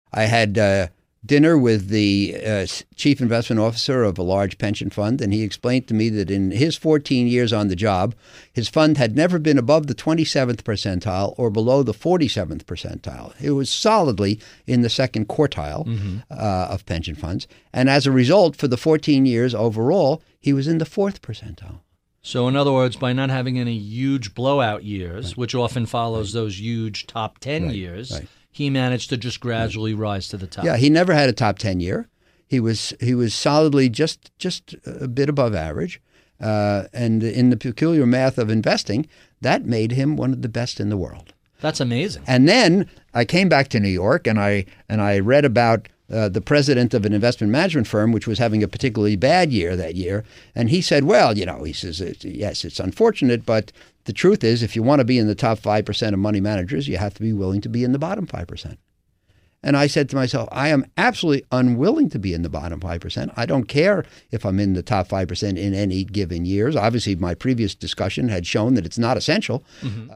Enjoy this classic clip from Howard Marks’ interview with Barry Ritholtz on Bloomberg’s Masters In Business from 2019.